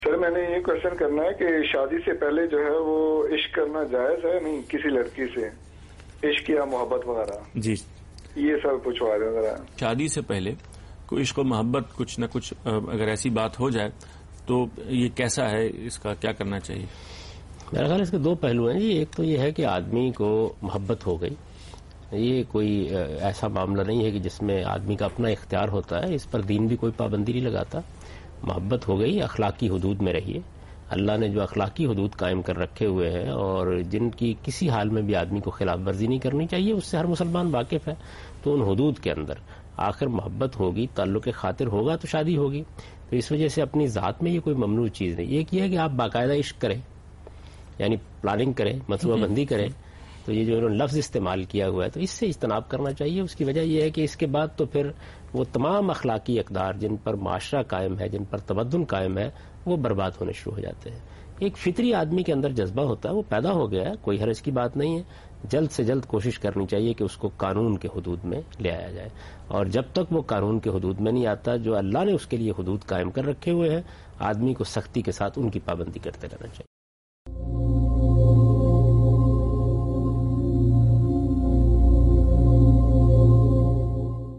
Category: TV Programs / Aaj Tv / Miscellaneous /
Question and Answers with Javed Ahmad Ghamidi in urdu